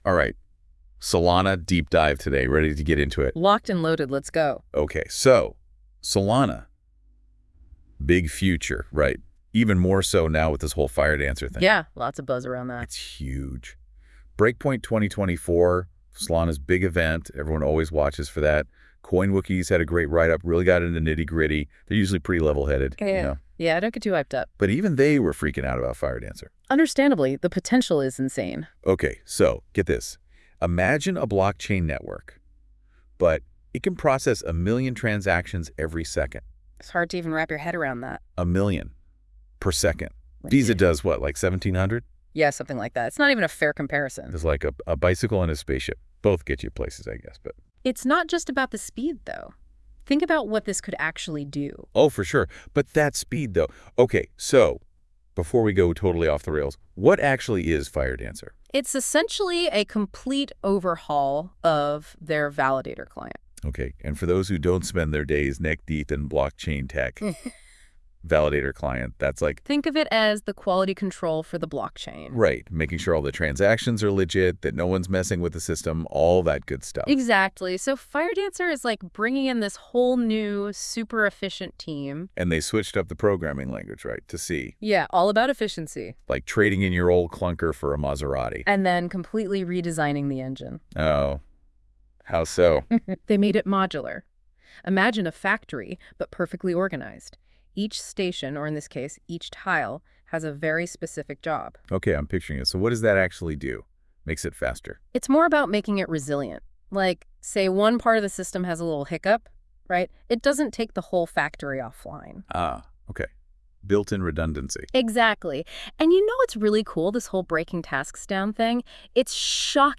Podcast Discussion: Deep Dive Into This Article.